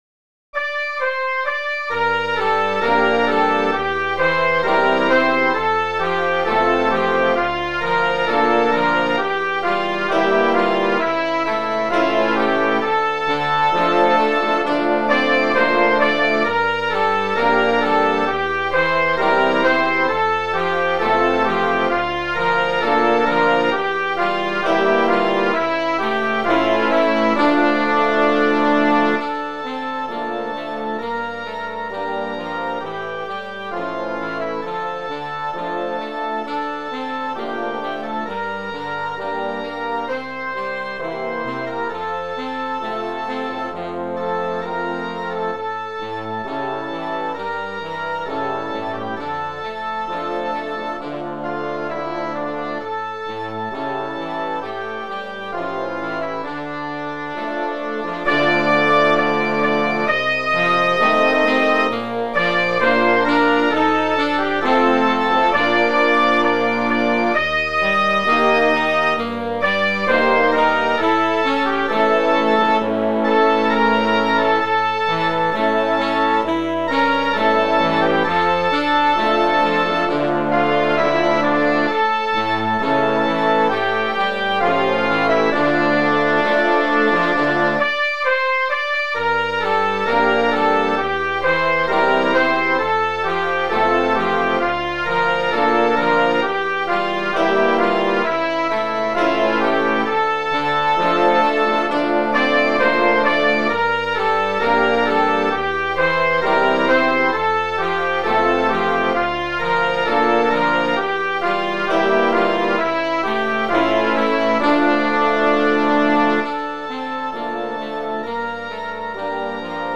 Inni Religiosi per banda
Inno Tradizionale